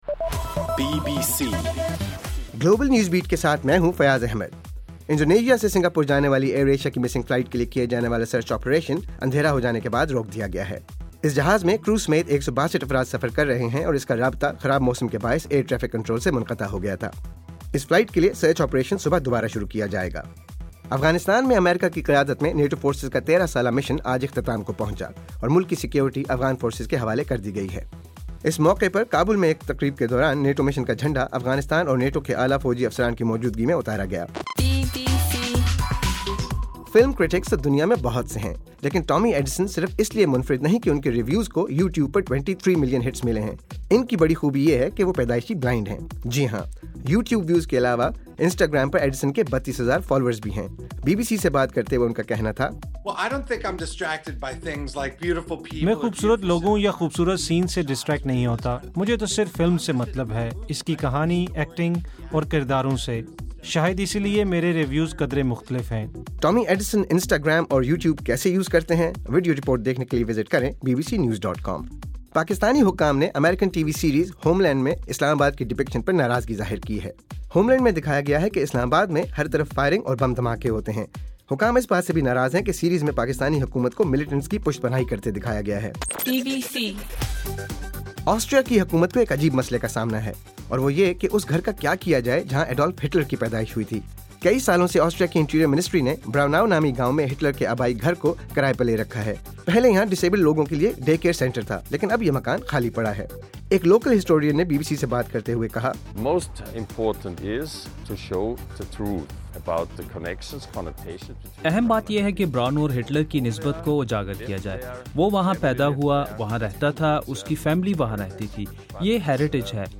دسمبر 28: رات 8 بجے کا گلوبل نیوز بیٹ بُلیٹن